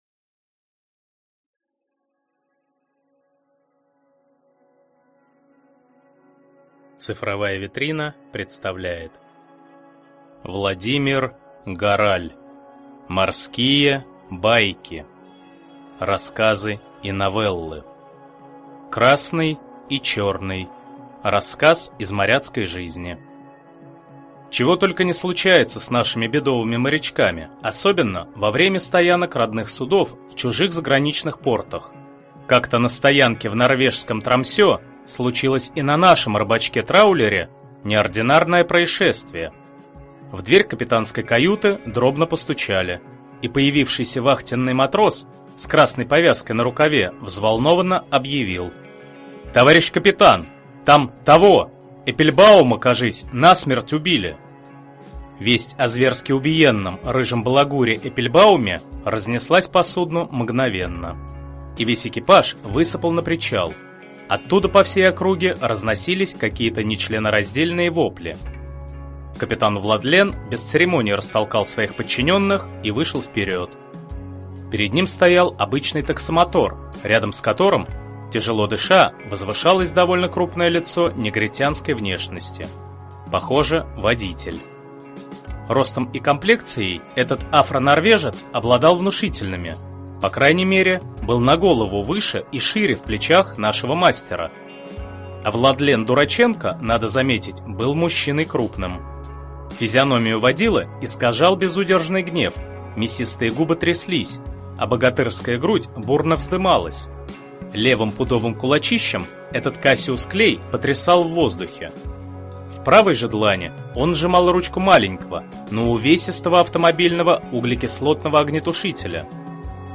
Аудиокнига Морские байки. Рассказы и новеллы | Библиотека аудиокниг